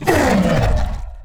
hurt2.wav